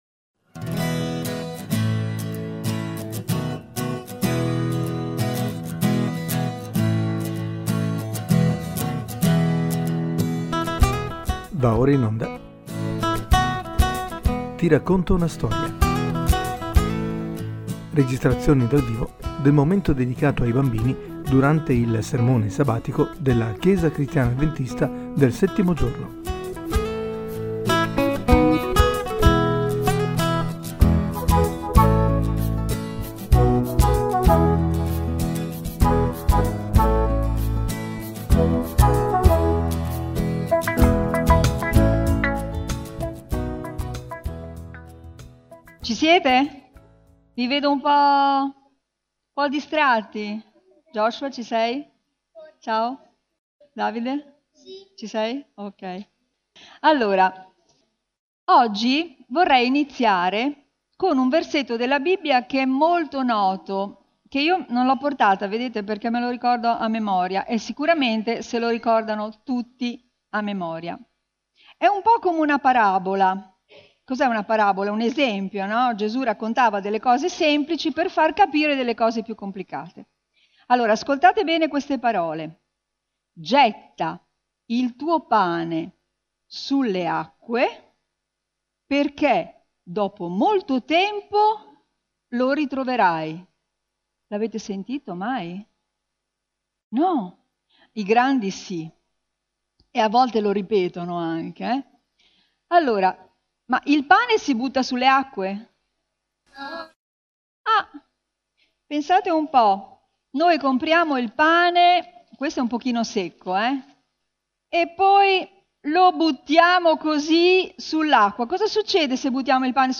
Ti racconto una storia 28: (25.03.2017) Registrazioni dal vivo della storia dedicata ai bambini durante il sermone sabatico della chiesa cristiana avventista del settimo giorno di Forlì.